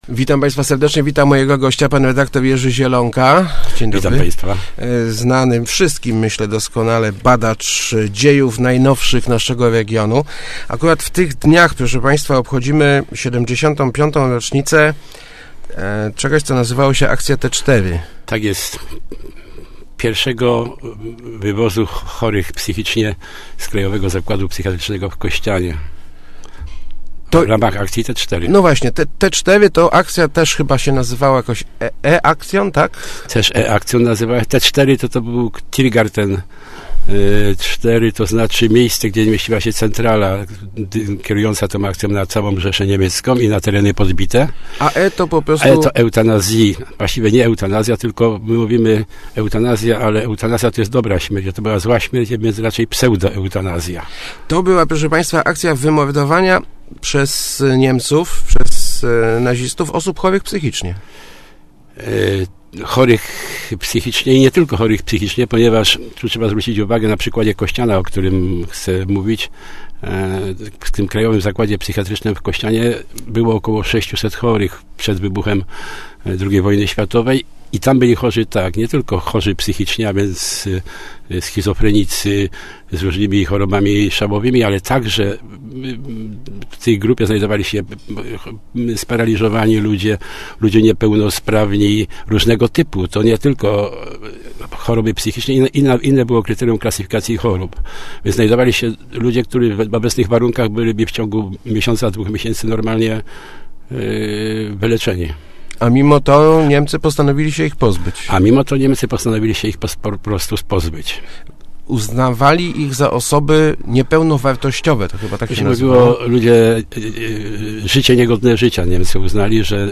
wywiadu